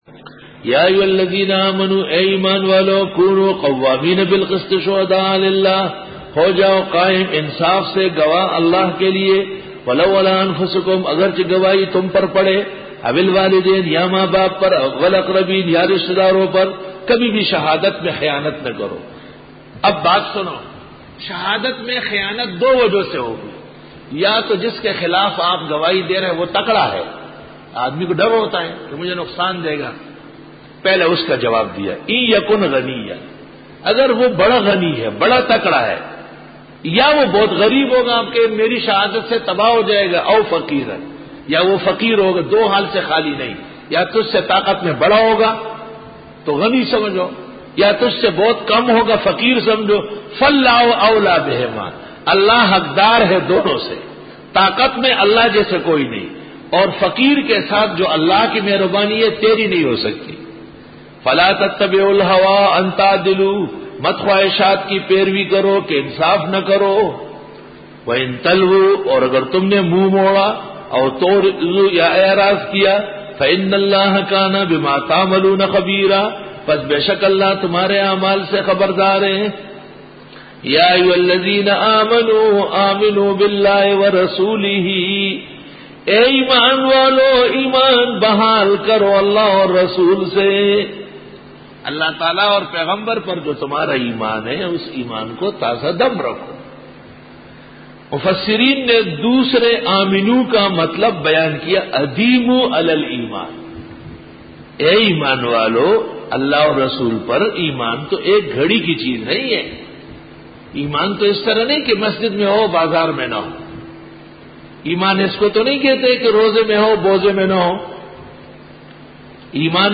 سورۃ النساء-رکوع-20 Bayan